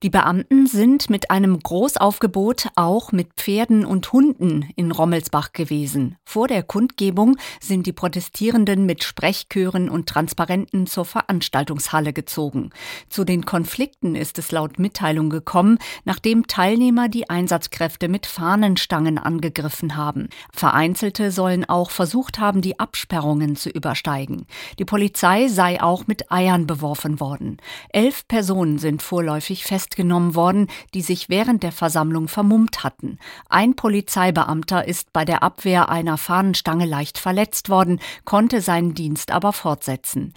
Tausende Menschen haben mit Sprechchören und Transparenten gegen den Auftritt von AfD-Politiker Höcke in Reutlingen protestiert. Die Polizei zeigte starke Präsenz - es gab Festnahmen.